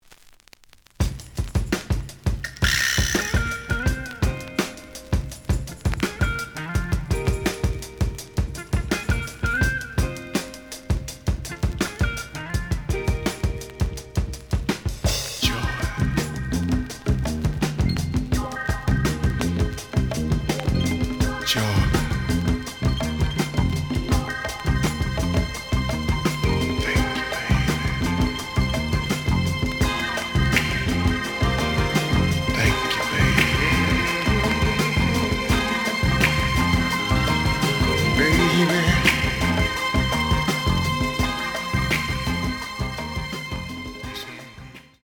The audio sample is recorded from the actual item.
●Genre: Funk, 70's Funk
Some click noise on both sides due to scratches.